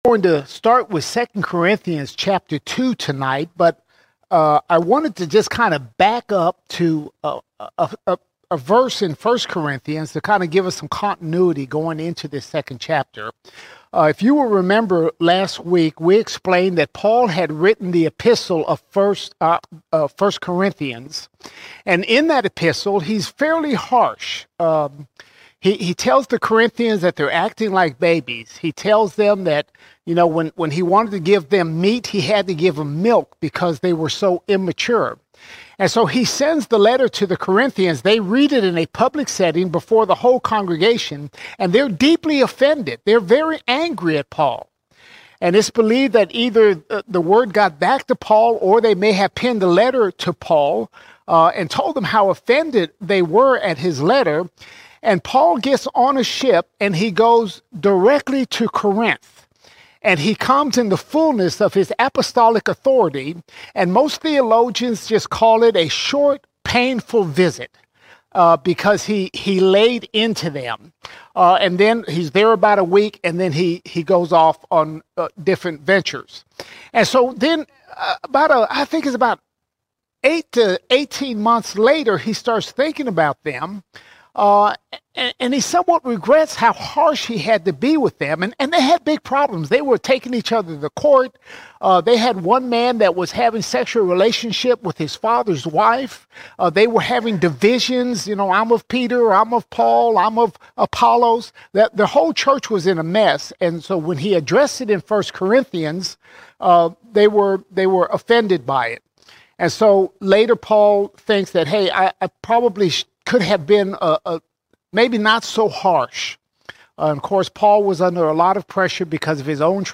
13 March 2025 Series: 2 Corinthians All Sermons 2 Corinthians 2:1 - 3:6 2 Corinthians 2:1 – 3:6 Paul encourages forgiveness for a repentant sinner and emphasizes his sincerity in spreading the gospel.